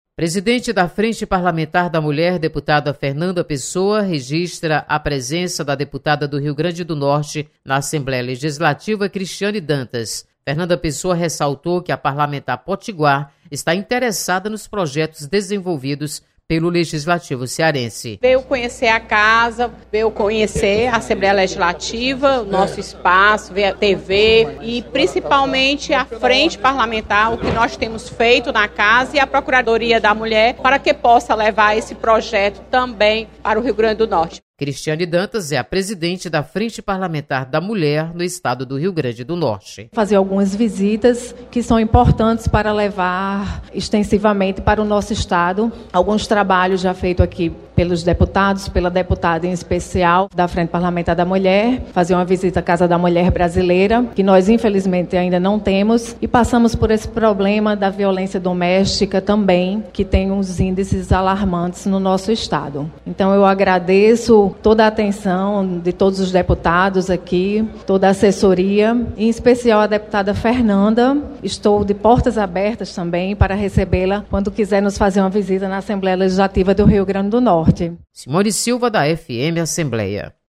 Deputada Fernanda Pessoa destaca defesa dos direitos da mulher em frentes parlamentares. Repórter